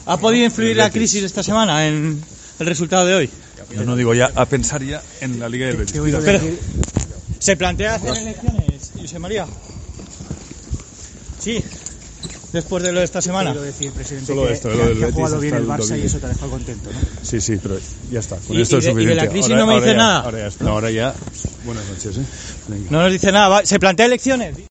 AUDIO: El presidente del FC Barcelona llegó al Aeropuerto de Barcelona de madrugada procedente de Bilbao y no quiso hablar sobre crisis ni elecciones